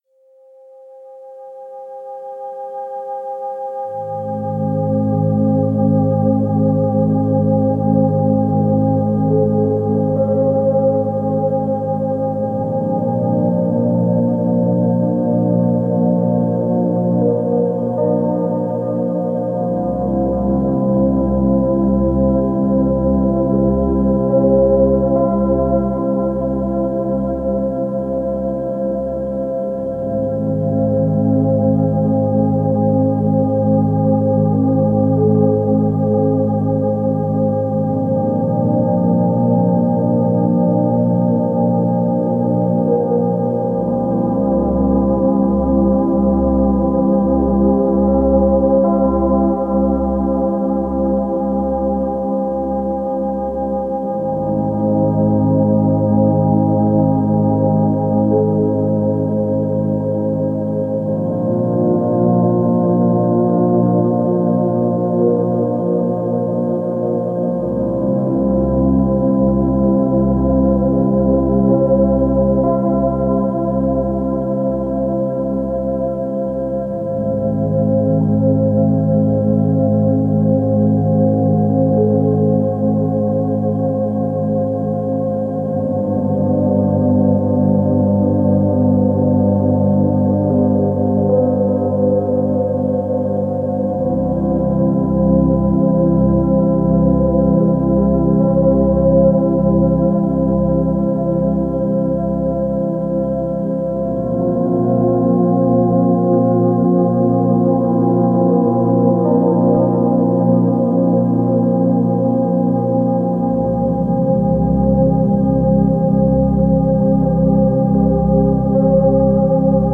528Hz – فرکانس 528 هرتز
در این فصل می‌خوایم براتون یک سری موسیقی با فرکانس‌های مختلف قرار بدیم که بهشون تون هم می‌گن.
به بعضی از این ها اصطلاحا می‌گن Solfeggio Frequencies که ترجمش فرکانس‌های سلفژی میشه که به فرکانس‌های خاصی اتلاق میشه.
528Hz.mp3